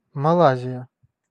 Ääntäminen
Ääntäminen US US : IPA : /məˈleɪʒə/ Lyhenteet ja supistumat (laki) Malay.